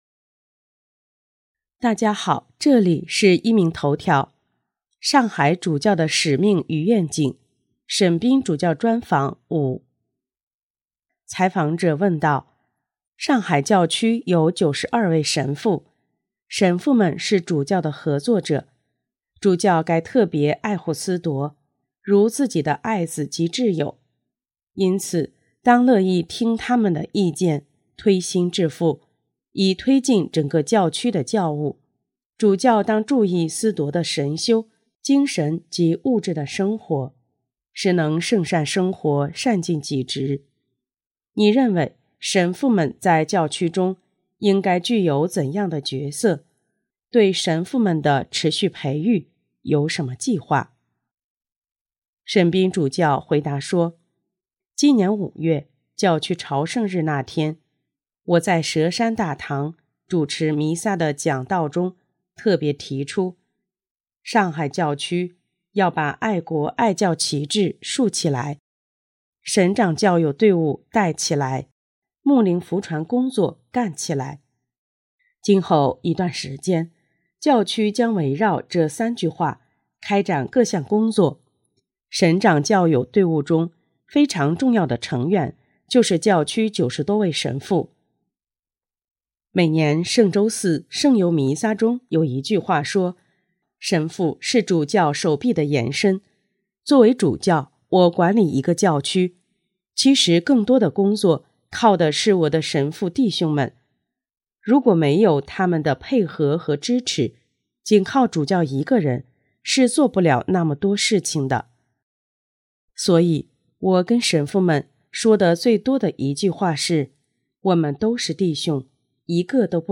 【壹明头条】| 沈斌主教专访（五）：如果神父们在某些事上的理念与您不一致，您会怎么处理？